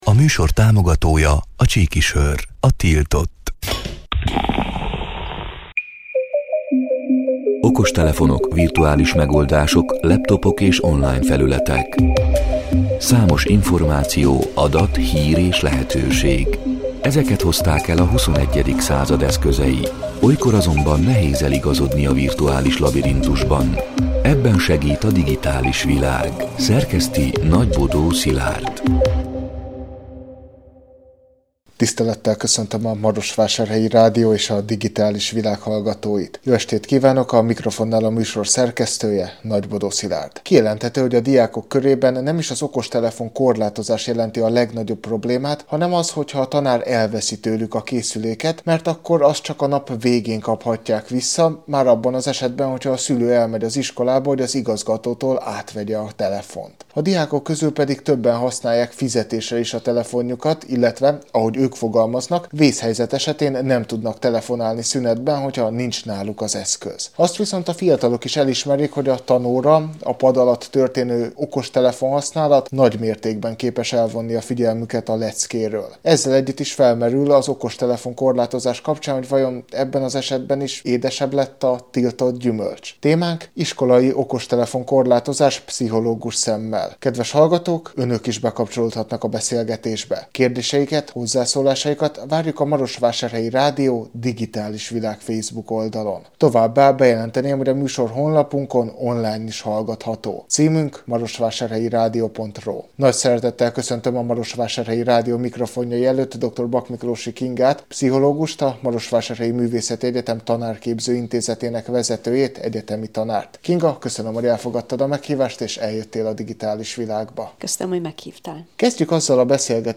A Marosvásárhelyi Rádió Digitális Világ (elhangzott: 2024. december 10-én, kedden este nyolc órától) c. műsorának hanganyaga: